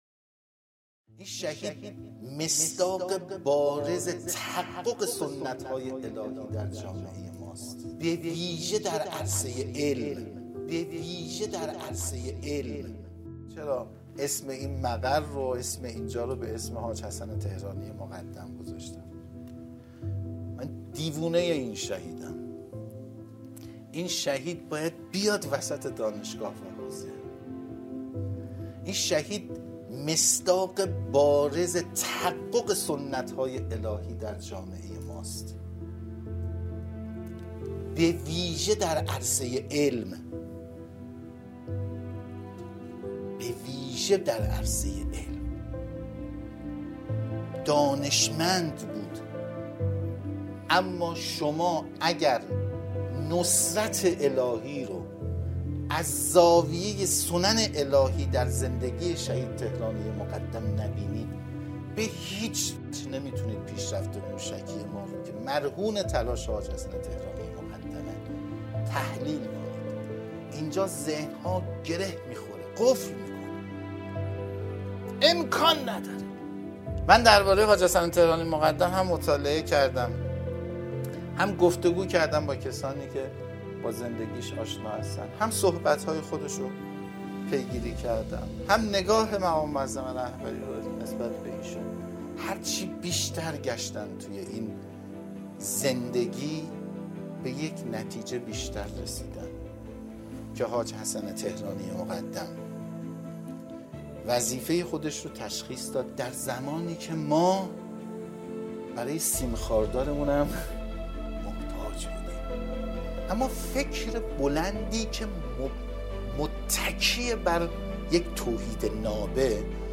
سخنرانی‌های